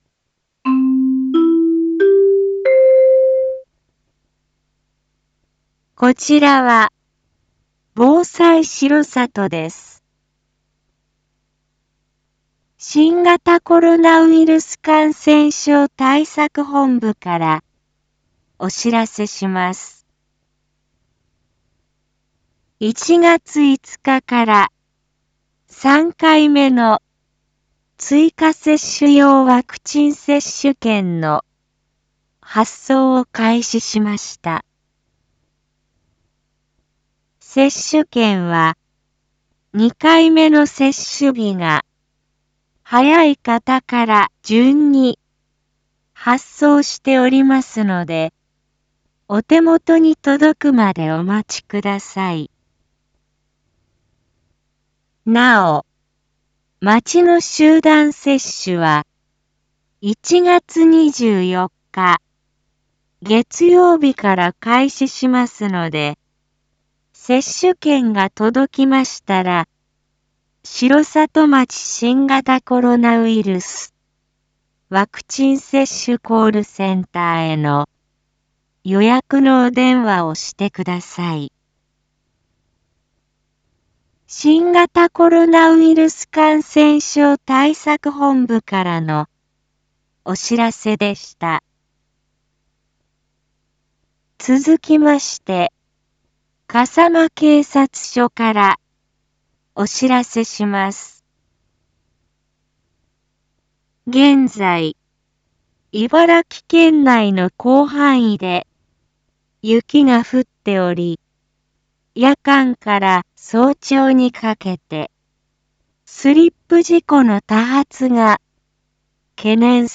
一般放送情報
Back Home 一般放送情報 音声放送 再生 一般放送情報 登録日時：2022-01-06 19:02:50 タイトル：R4.1.6 19時 放送分 インフォメーション：こちらは、防災しろさとです。